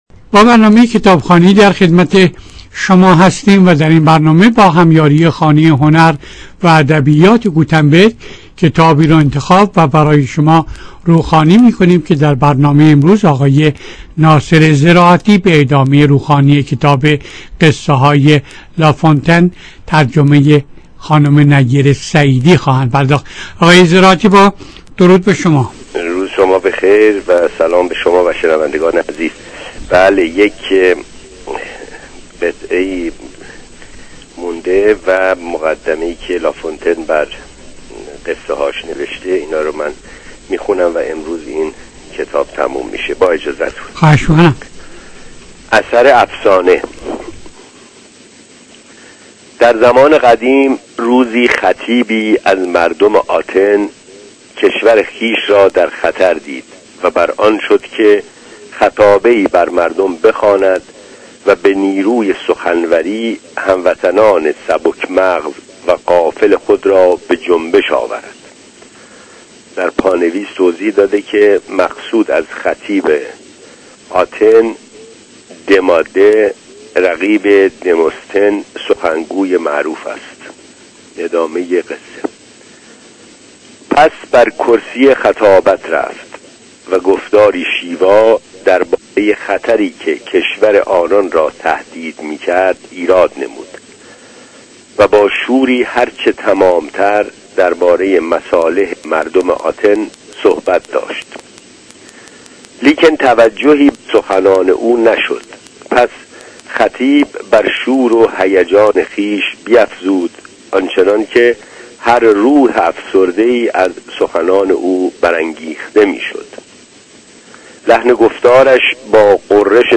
با هم این کتاب را می شنویم.